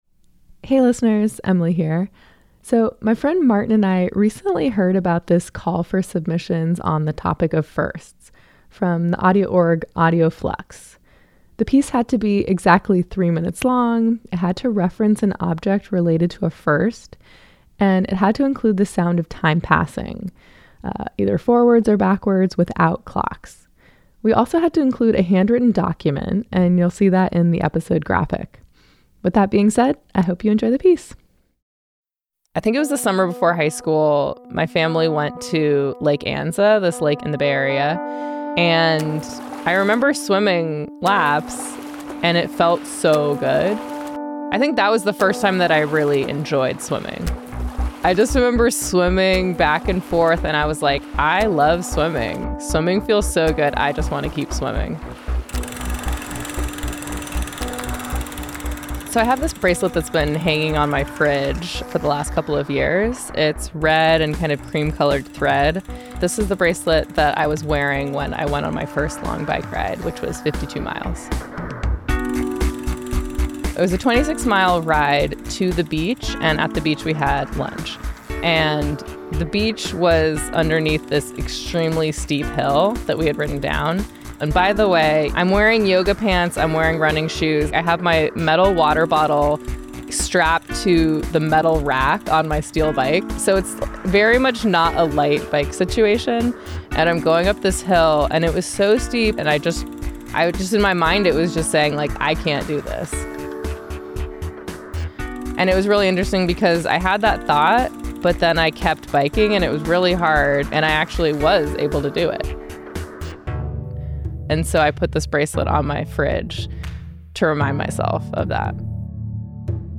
• Include the sound of time passing (forwards or backwards, no clocks please)